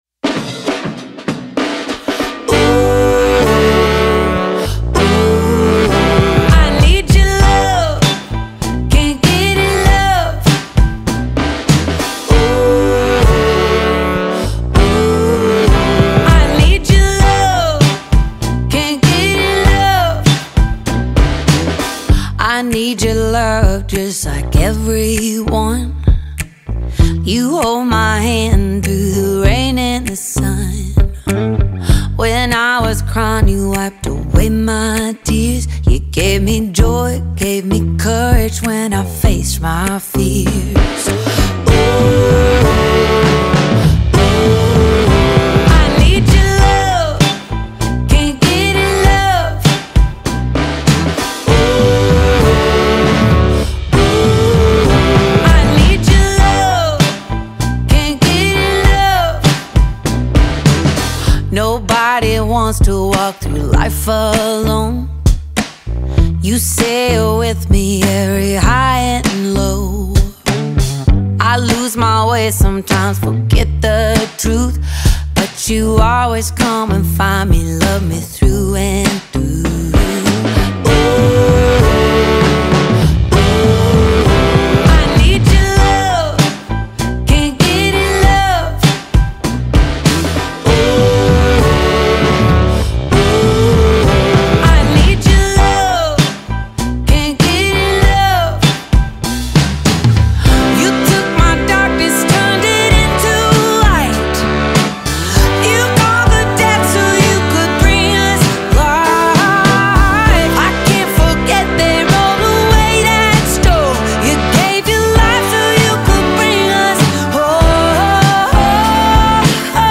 107 просмотров 39 прослушиваний 6 скачиваний BPM: 98